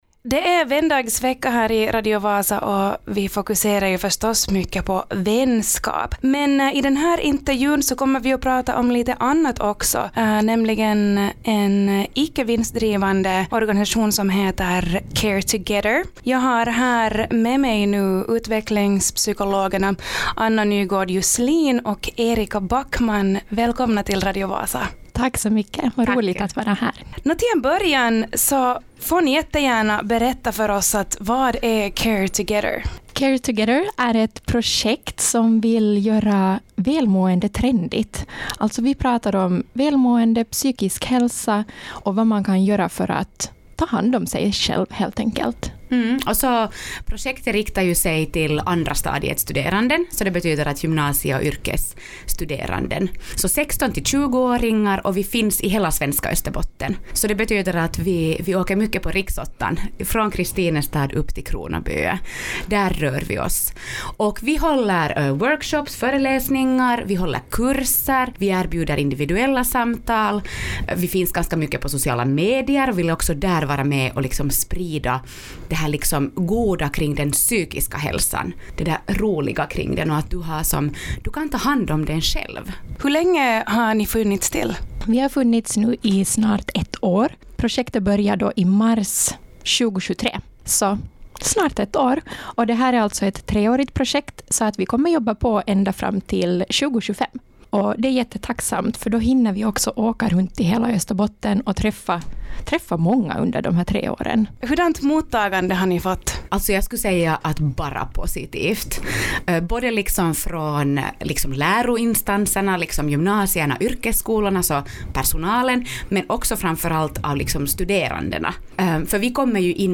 Lyssna på intervjun för att få mer info om deras verksamhet samt tips till just ditt välmående.